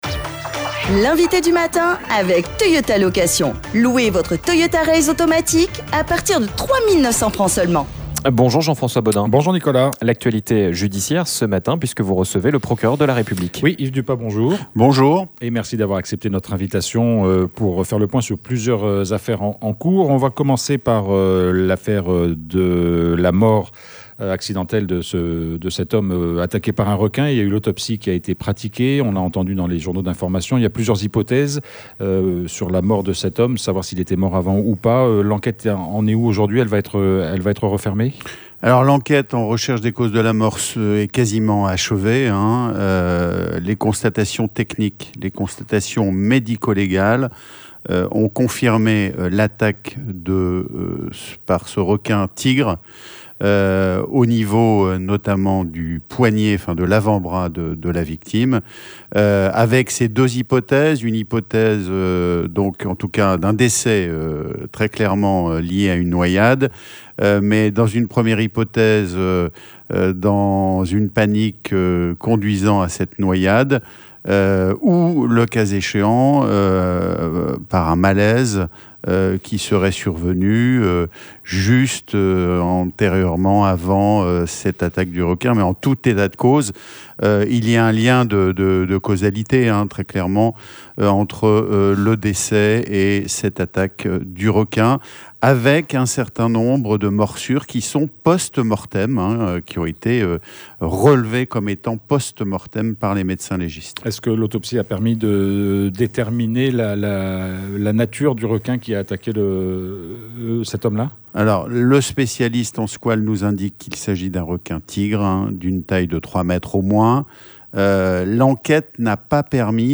Yves Dupas, le procureur de la République, était notre Invité du matin. Il évoque plusieurs affaires en cours, et réagi également à la publication du rapport de la commission nationale consultative des droits de l’homme sur les émeutes de mai 2024.